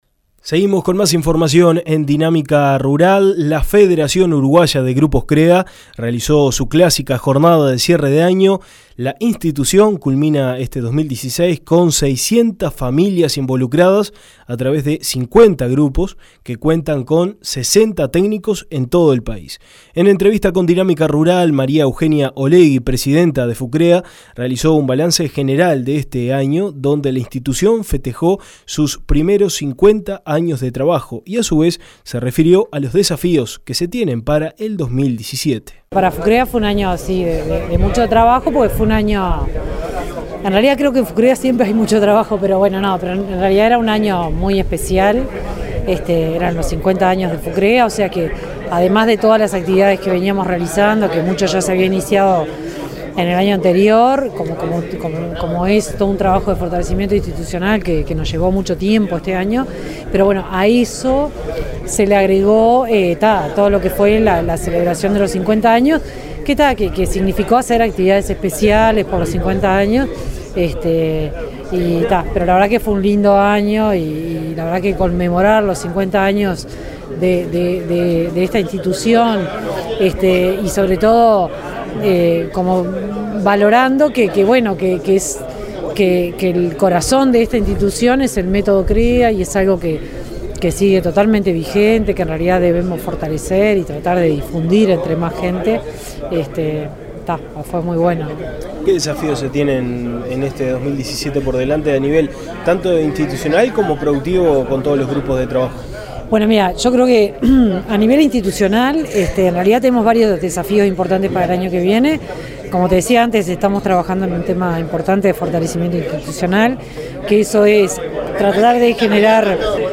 La Federación Uruguaya de Grupos Crea realizó su clásica jornada de cierre de año, la institución culmina este 2016 con 600 familias involucradas a través de 50 grupos, que son asistidos por 60 técnicos en todo el país. En entrevista con Dinámica Rural